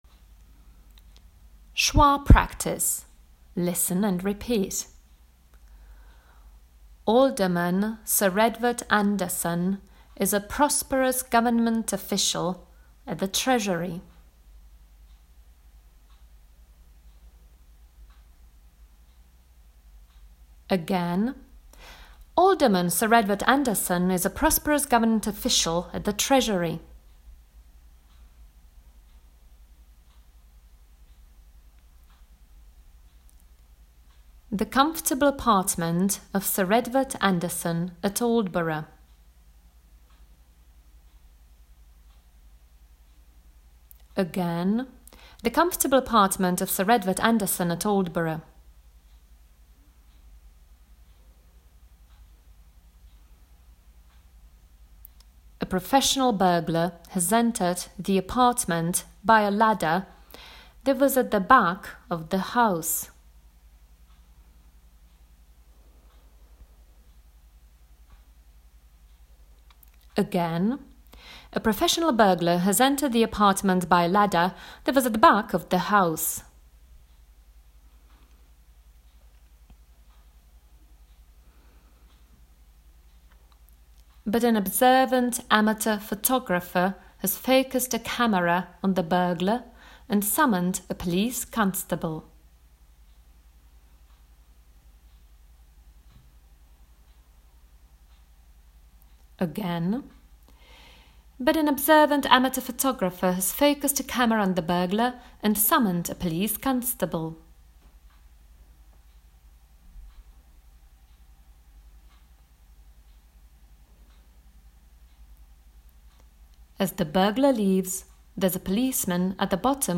Schwa Practice in Context: Recording
SCHWA_practice_in_context-recording.m4a